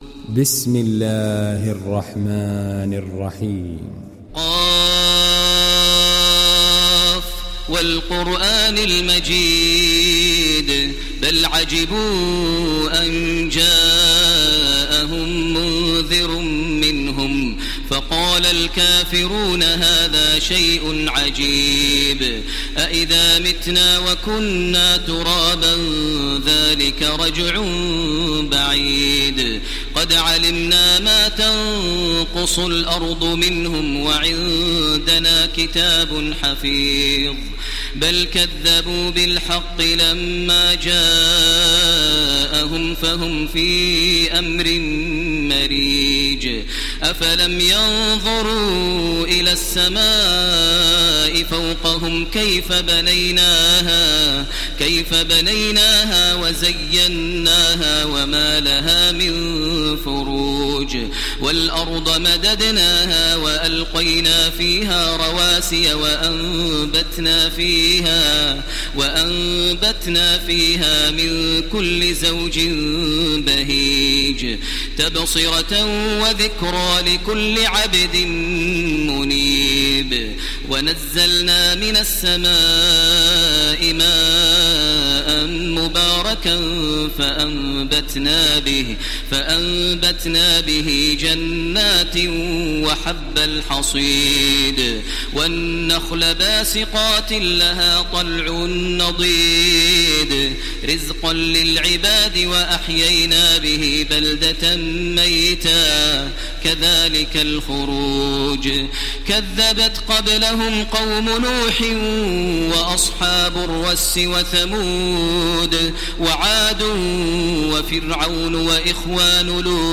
تحميل سورة ق mp3 بصوت تراويح الحرم المكي 1430 برواية حفص عن عاصم, تحميل استماع القرآن الكريم على الجوال mp3 كاملا بروابط مباشرة وسريعة
تحميل سورة ق تراويح الحرم المكي 1430